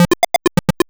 retro_synth_beeps_groove_02.wav